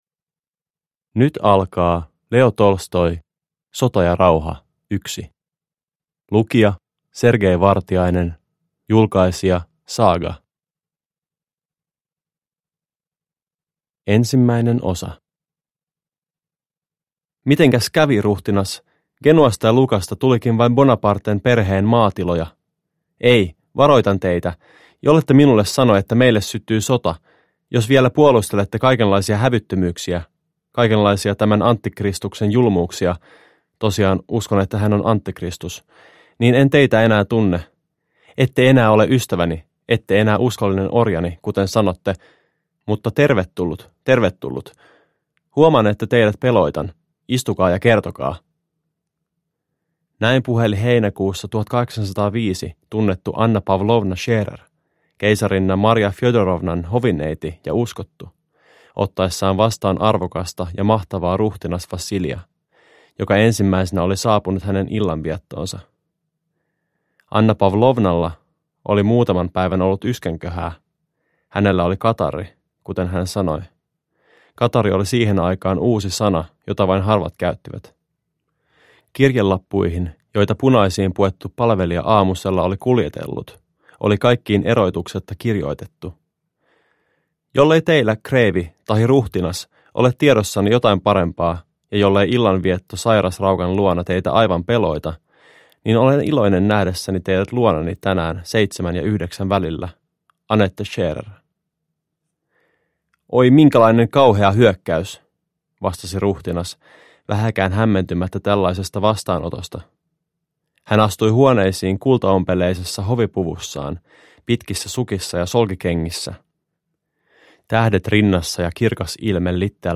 Sota ja rauha 1 (ljudbok) av Leo Tolstoi